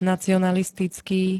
nacionalistický [-t-] -ká -ké príd.
Zvukové nahrávky niektorých slov